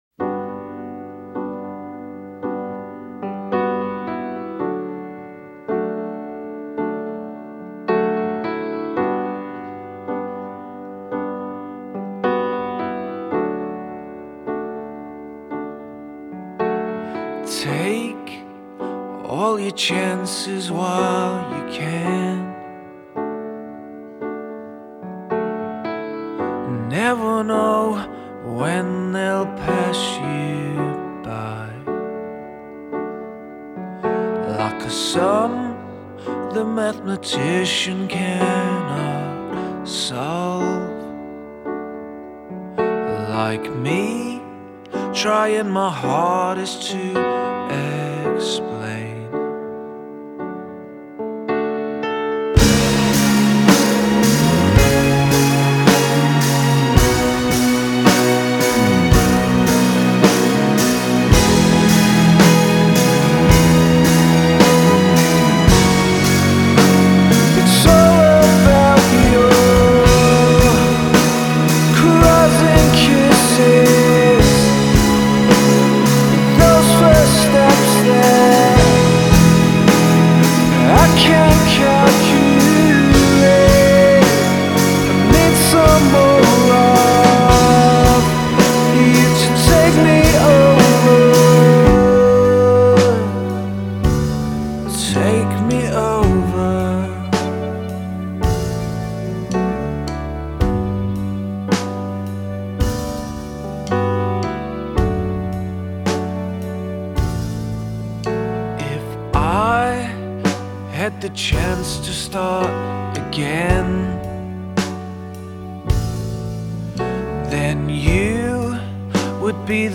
Electronic rock Alternative Rock